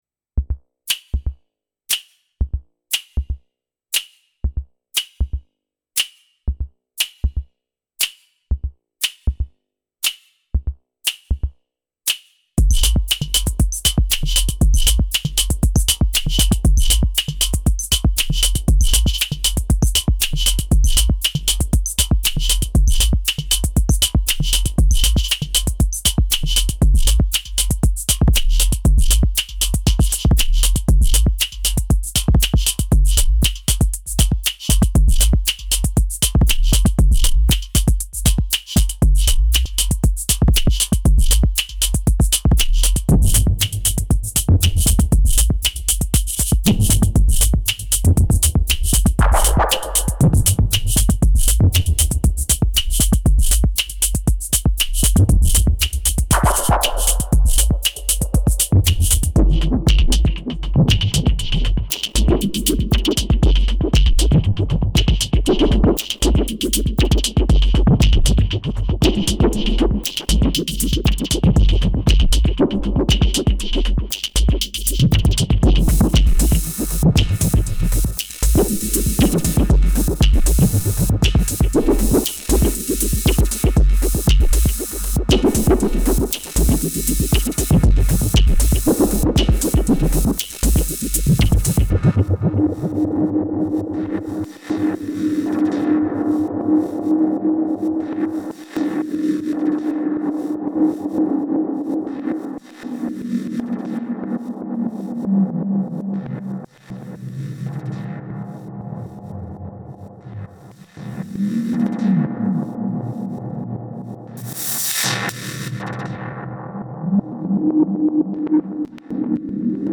This is done entirely with the analog noise, impulse and cymbal sounds on track 9-13 in the Syntakt, with no post-processing.